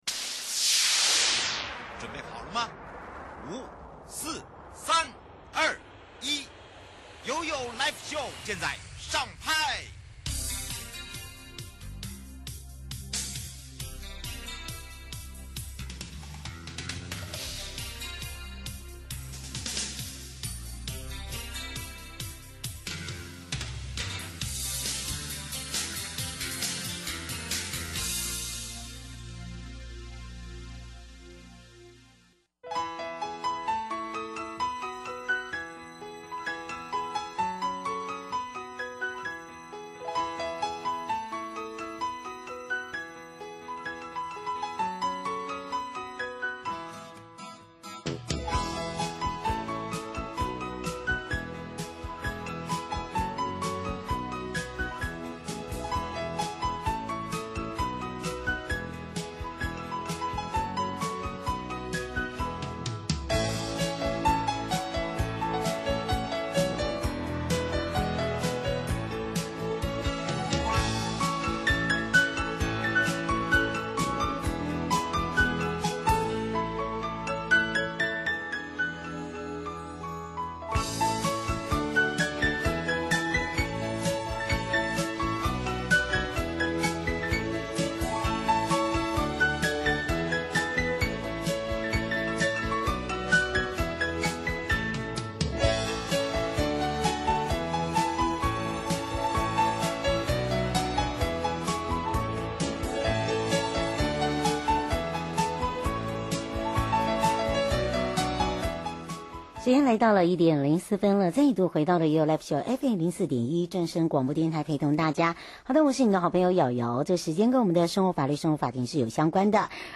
受訪者： 1. 台北地檢黃珮瑜主任檢察官 2. 法務部矯正署黃俊棠署長 節目內容： 1.